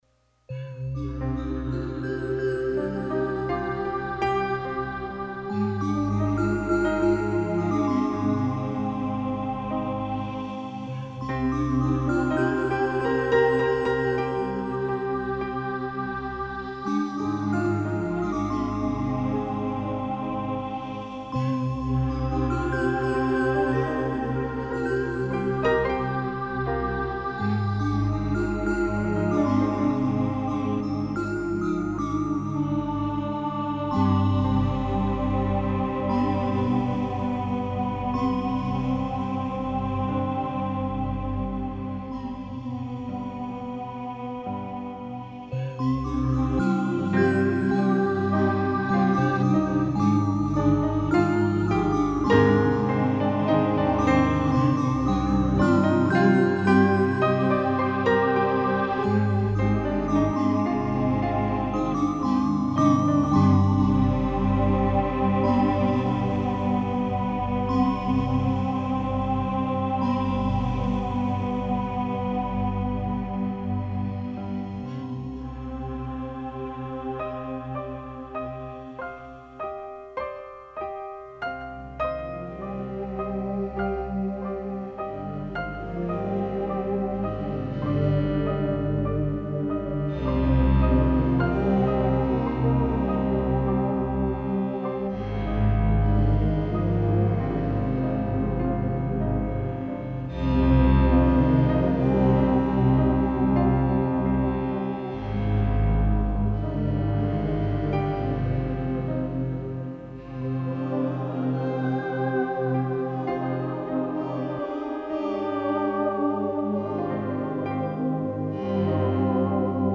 AN INSTRUMENTAL